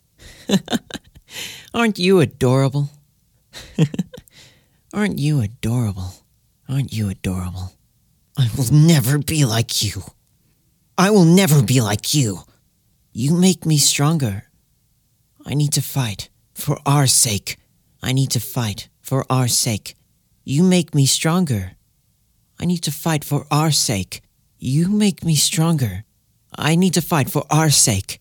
Voice: Mid-high, carefree, possibly also friendly and outgoing.